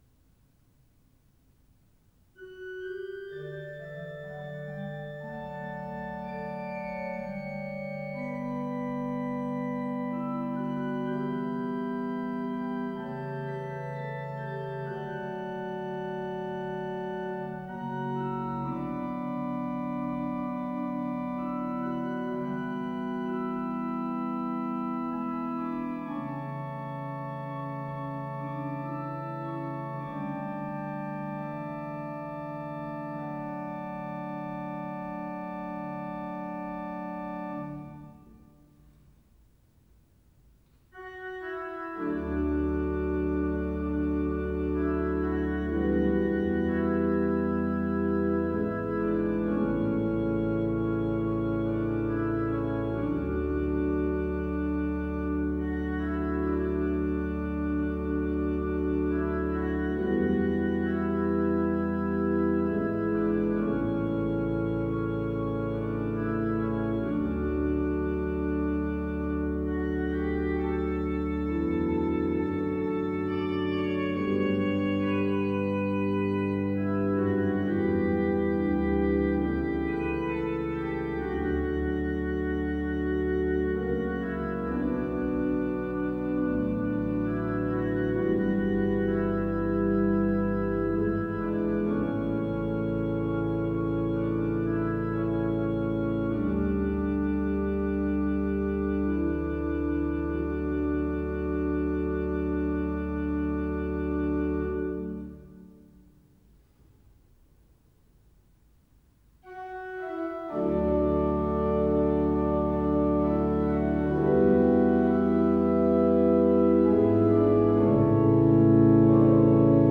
Kopervik kirke
Kopervik kirke er en helt ny kirke med god romklang, lys og romslig.
I svellverket er det flotte fløyter.
Blott en dag En fin salme.
Kopervik kirke   ZOOM H4n Pro 11.06.2022